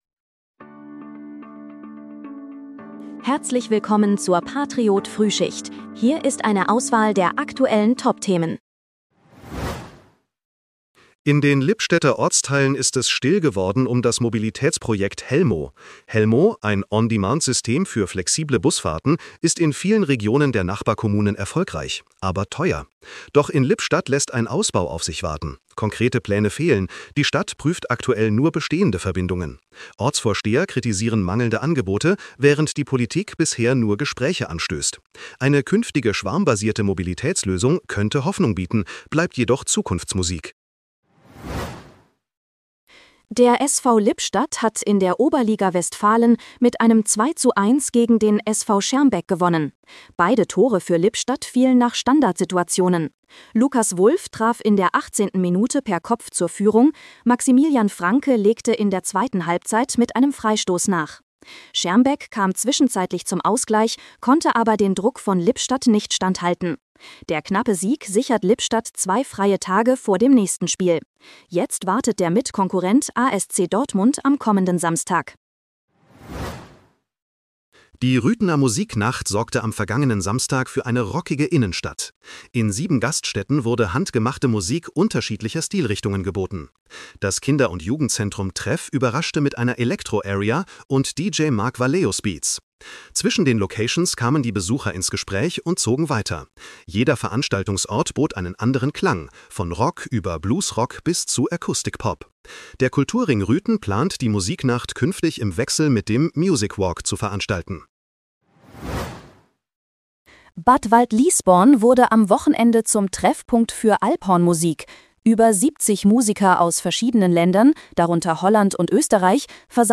Dein morgendliches News-Update